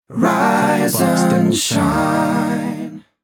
“Rise and shine” Clamor Sound Effect
Can also be used as a car sound and works as a Tesla LockChime sound for the Boombox.